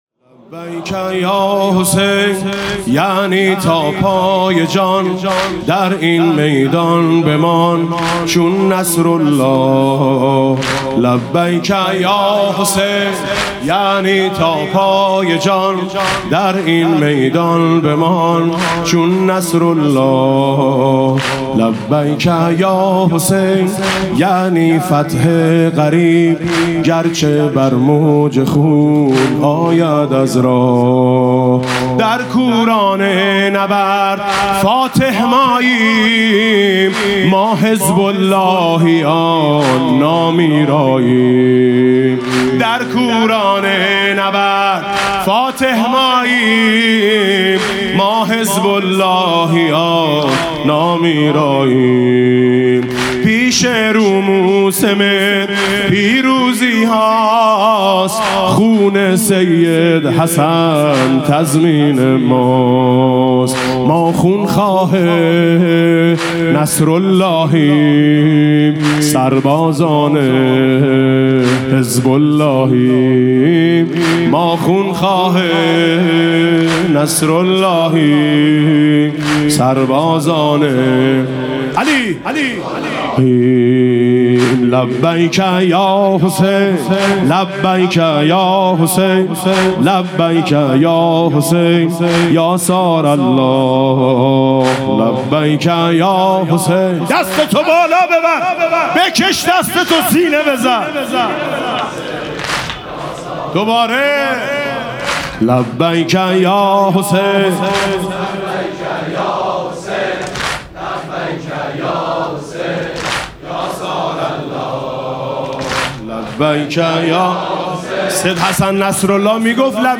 مسیر پیاده روی نجف تا کربلا [عمود ۹۰۹]
مناسبت: ایام پیاده روی اربعین حسینی